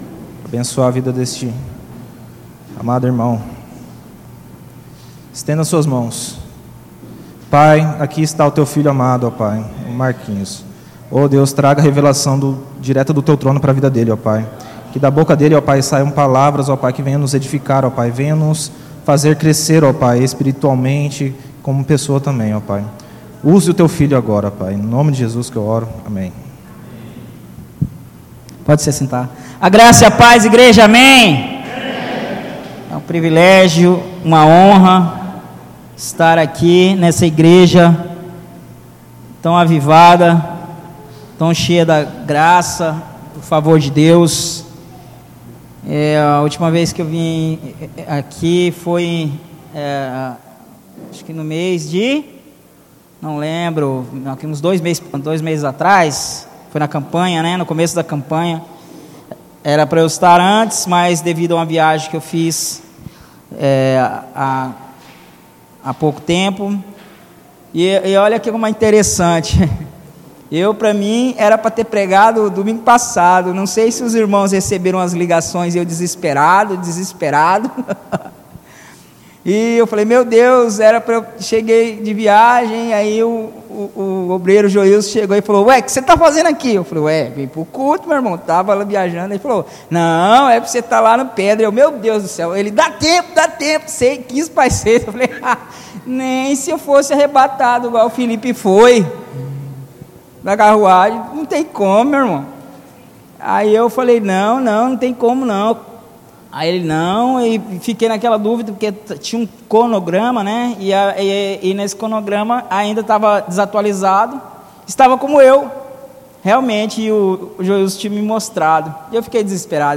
Culto de Celebração